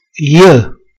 j = si pronuncia come iê con la "e" di "je (io)" francese
pronuncia della j.mp3